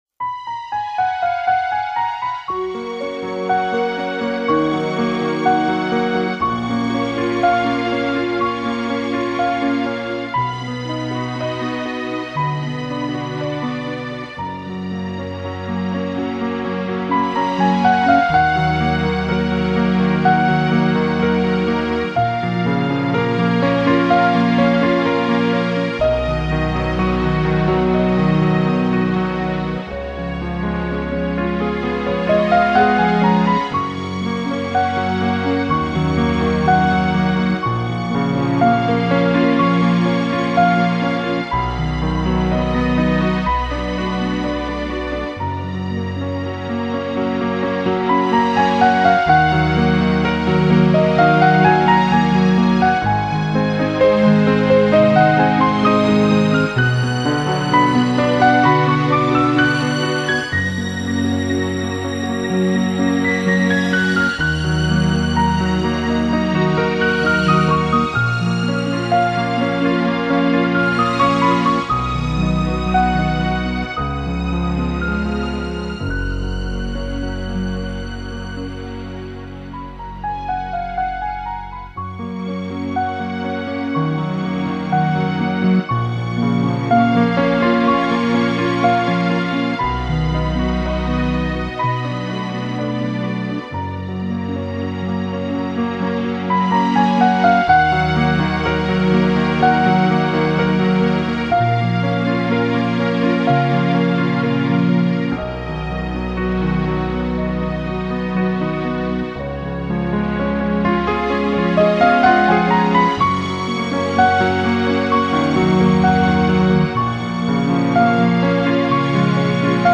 Genre:New Age, Instrumental, Neo-Classical, Piano